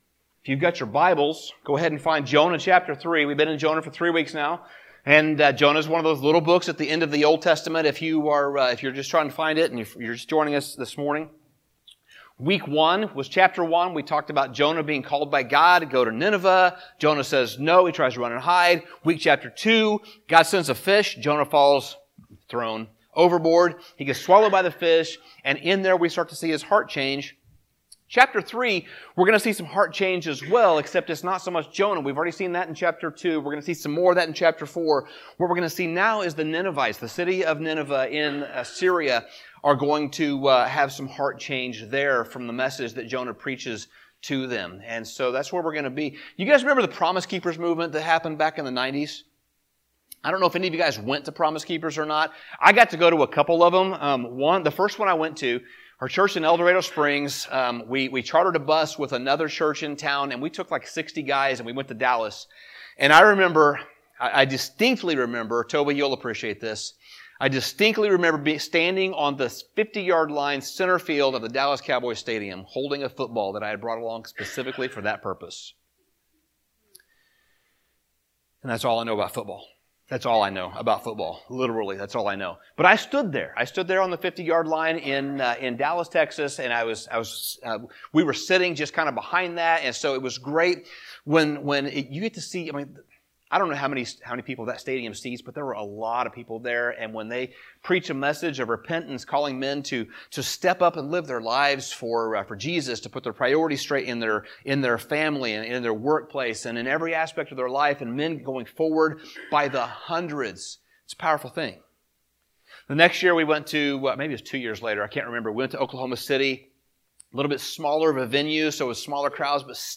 Jan 14, 2023 Jonah: The City MP3 PDF SUBSCRIBE on iTunes(Podcast) Notes Sermons in this Series Series Summary The book of Jonah is unique among the Old Testament prophets.